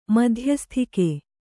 ♪ madhyasthike